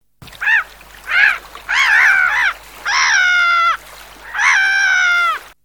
Mouette rieuse
Larus ridibundus
mouette.mp3